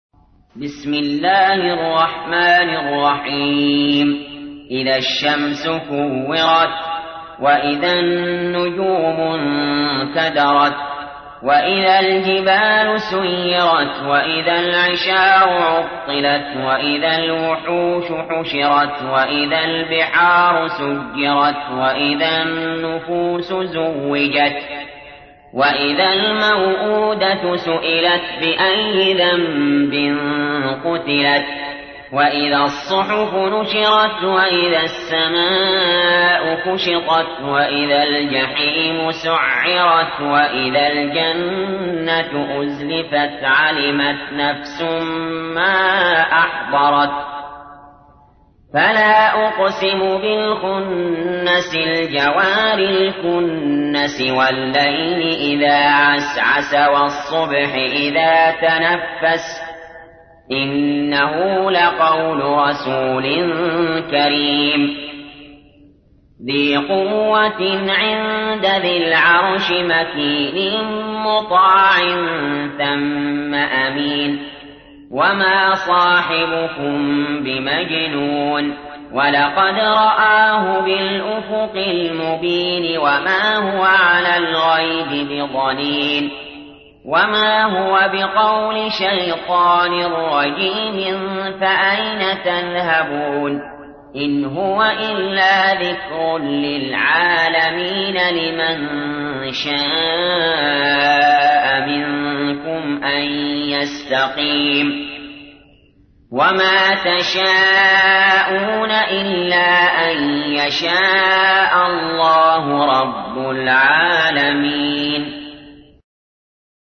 تحميل : 81. سورة التكوير / القارئ علي جابر / القرآن الكريم / موقع يا حسين